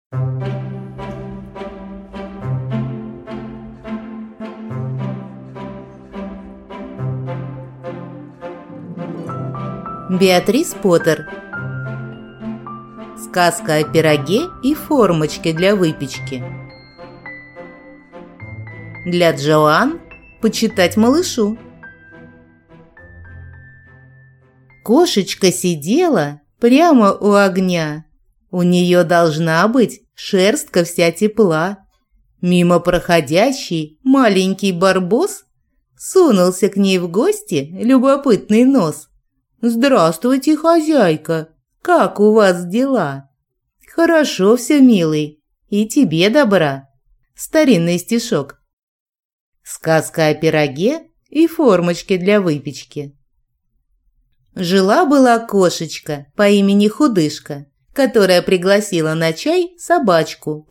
Аудиокнига Сказка о пироге и формочке для выпечки | Библиотека аудиокниг